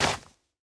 Minecraft Version Minecraft Version 1.21.5 Latest Release | Latest Snapshot 1.21.5 / assets / minecraft / sounds / block / soul_soil / step4.ogg Compare With Compare With Latest Release | Latest Snapshot
step4.ogg